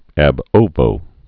(ăb ōvō)